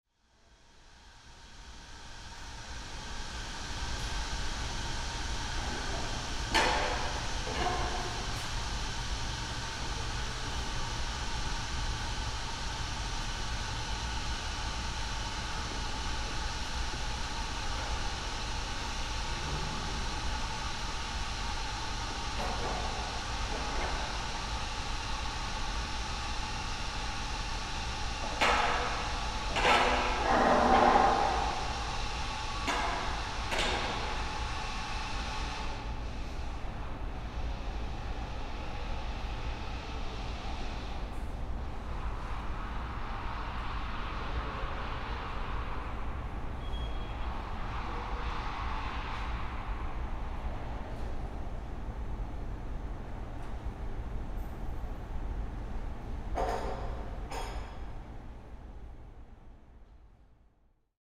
British Library at 8am
Tags: Sound Map in London London sounds UK Sounds in London London